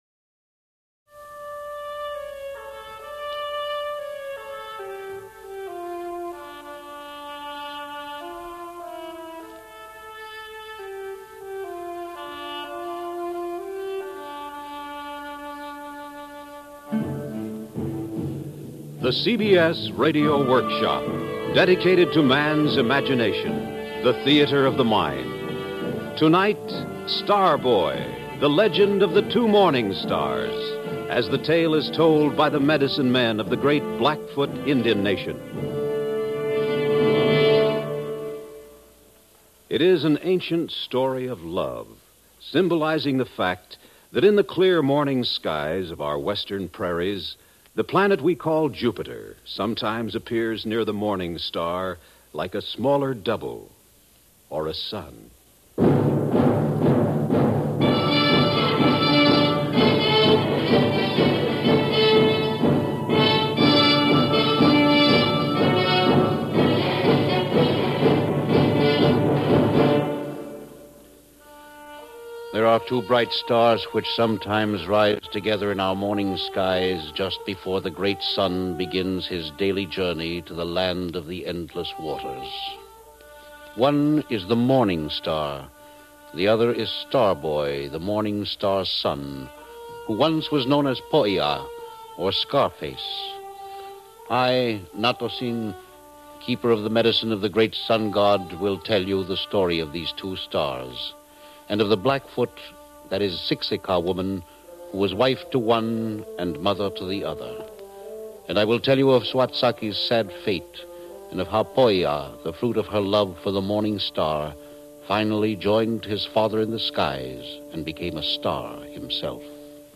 CBS Radio Workshop with host and narrator William Conrad